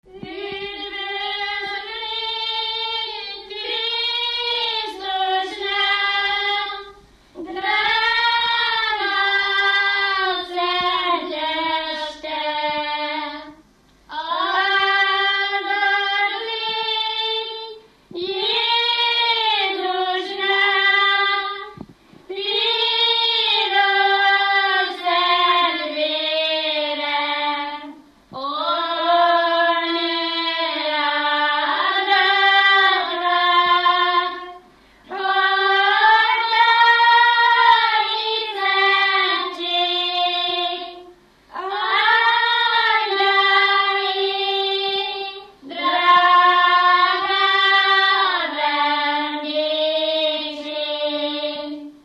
Felföld - Heves vm. - Istenmezeje
Stílus: 5. Rákóczi dallamkör és fríg környezete